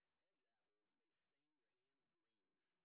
sp08_street_snr20.wav